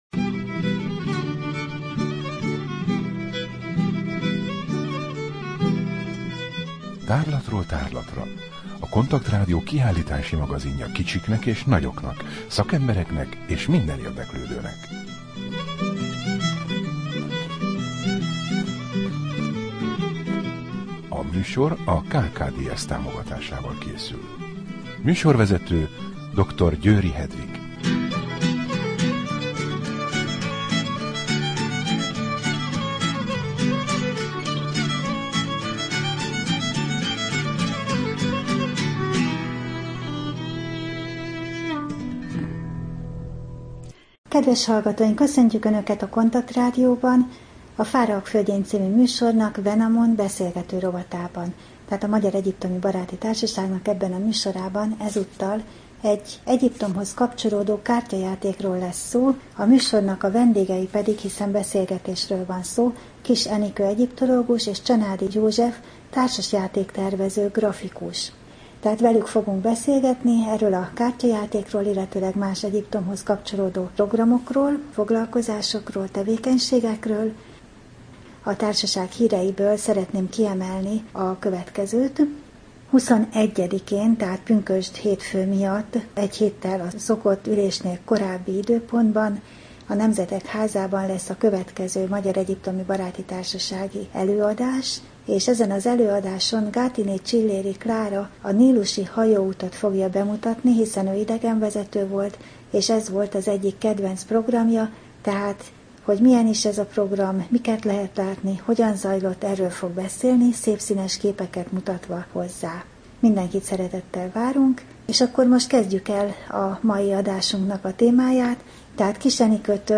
Wenamon beszélgető rovat: Beszélgetés egy kártyajátékról | Magyar Egyiptomi Baráti Társaság